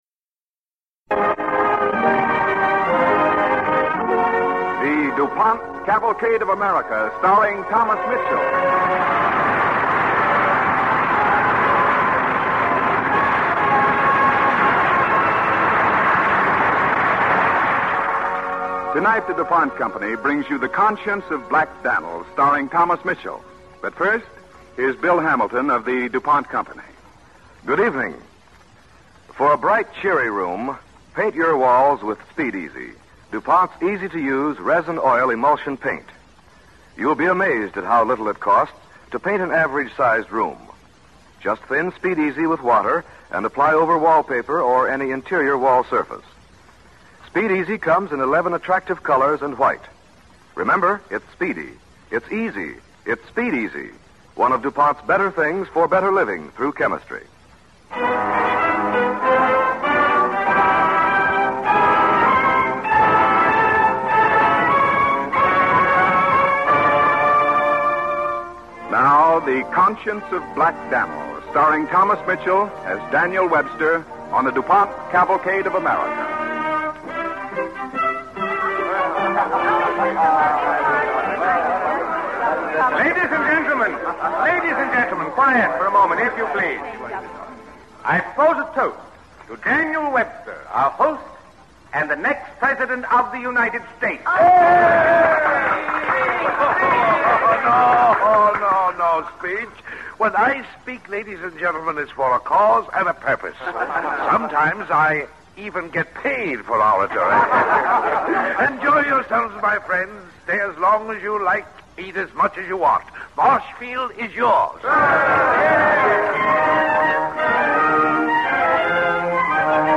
starring Thomas Mitchell and Barbara Weeks
Cavalcade of America Radio Program